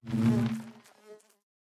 Minecraft Version Minecraft Version 1.21.5 Latest Release | Latest Snapshot 1.21.5 / assets / minecraft / sounds / block / beehive / work3.ogg Compare With Compare With Latest Release | Latest Snapshot